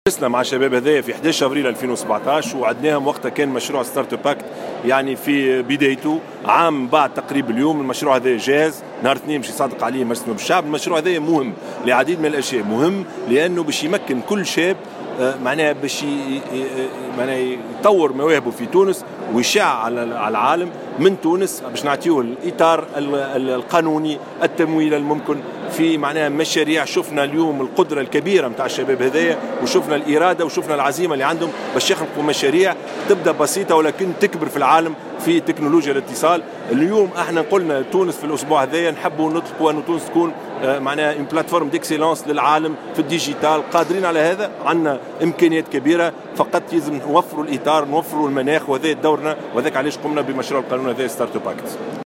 واوضح الشاهد، خلال لقاء تفاعلي جمعه، بمتحف باردو، بمجموعة من الشباب الفاعلين وباعثي المؤسسات الناشئة في مجال الإقتصاد الرقمي وتكنولوجيات الإتصال، ان هذه الاسس تتمثل في توفير الاطار التشريعي والتمويل والموارد البشرية والمناخ الملائم لدفع الاستثمار في هذا المجال.